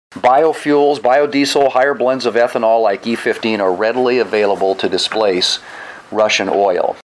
The republican lawmakers argue it’s time for the democratic White House to reverse policy and turn to more homegrown domestic energy like biofuels. Again, South Dakota Senator John Thune.